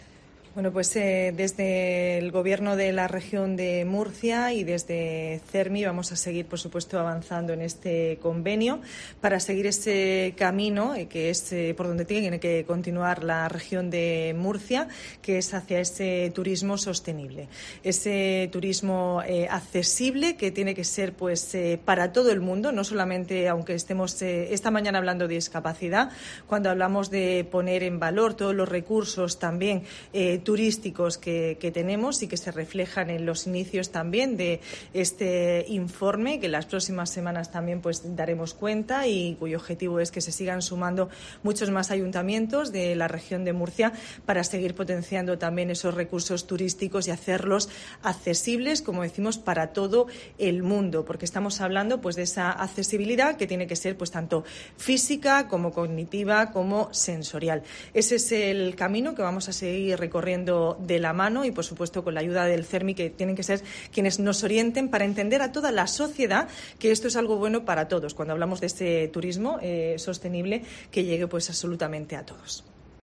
Carmen Conesa, consejera de Cultura, Turismo, Juventud y Deportes